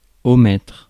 Ääntäminen
Synonyymit pass over leave out Ääntäminen US : IPA : [əʊ.mɪt] Haettu sana löytyi näillä lähdekielillä: englanti Käännös Ääninäyte Verbit 1. omettre France Määritelmät Verbit (transitive) To leave out or exclude.